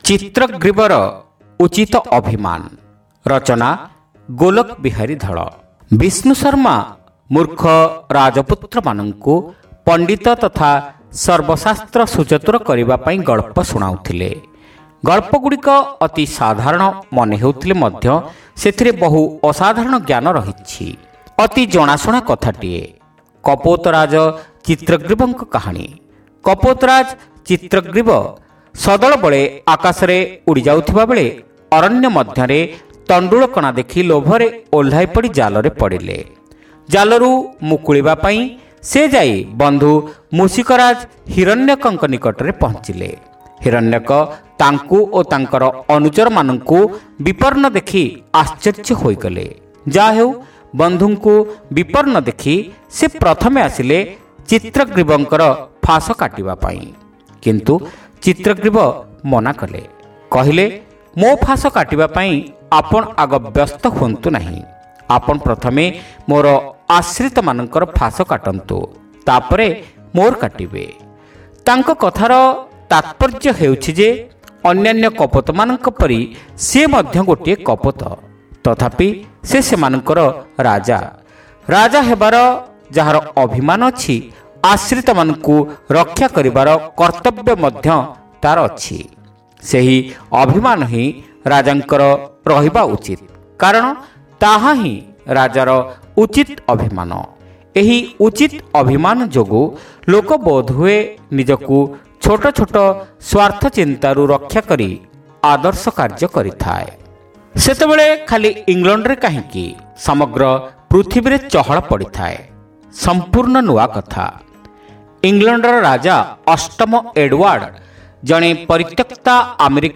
ଶ୍ରାବ୍ୟ ଗଳ୍ପ : ଚିତ୍ରଗ୍ରୀବର ଉଚିତ ଅଭିମାନ